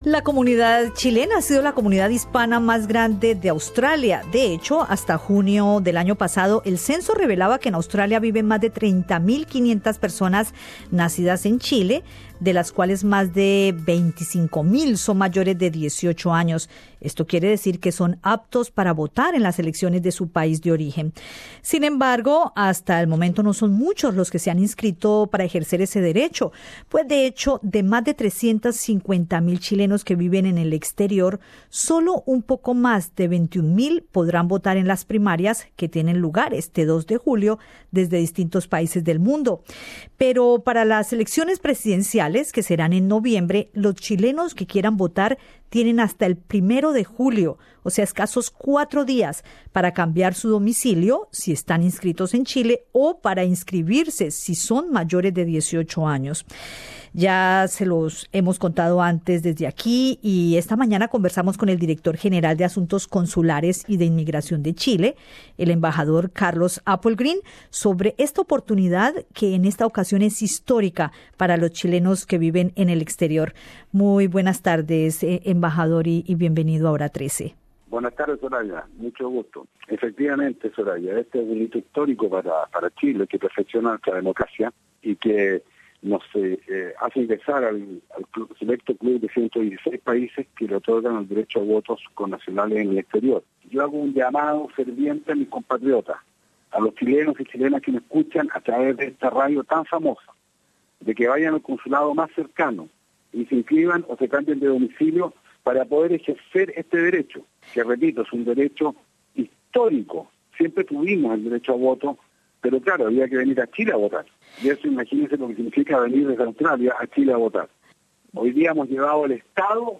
Escucha en el podcast la entrevista con el embajador Carlos Applegren, donde además informa que también pueden inscribirse los chilenos de segunda y tercera generación, que tengan su documento de identidad chileno.